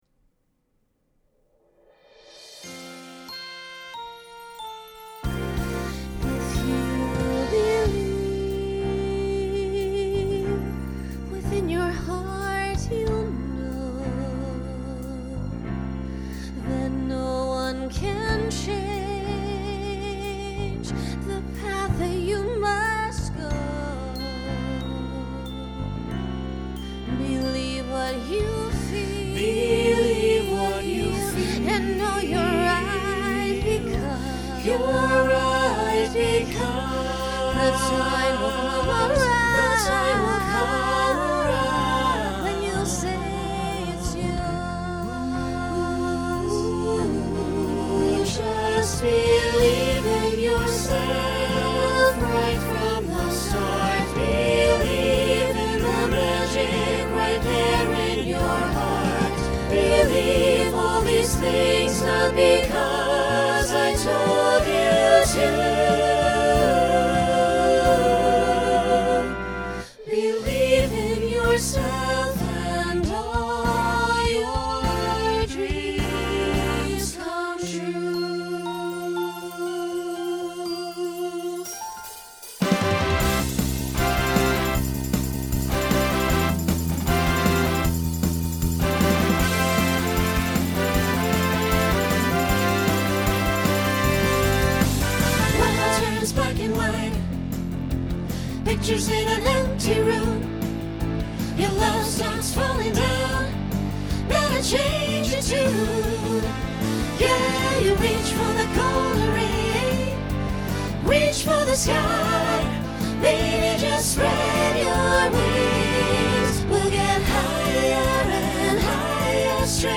Genre Broadway/Film , Rock Instrumental combo
Show Function Opener Voicing SATB